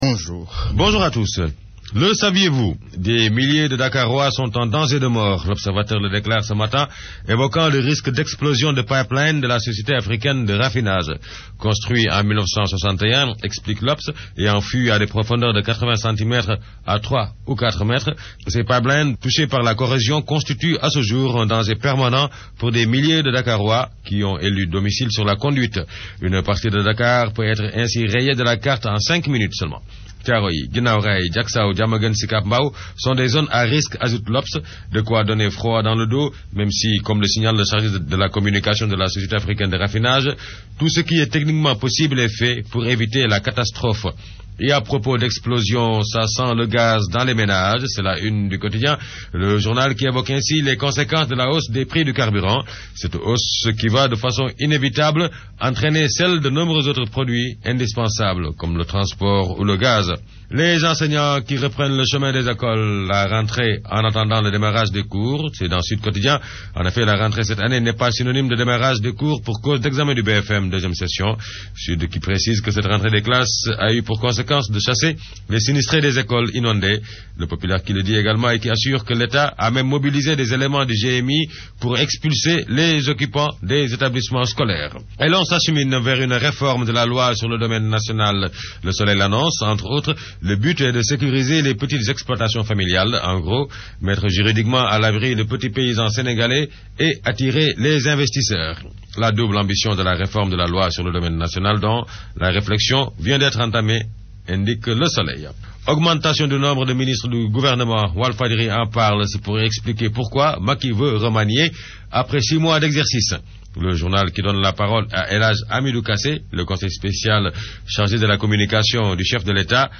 Revue de presse du 02 octobre 2012